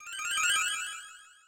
snd_spell_pacify.ogg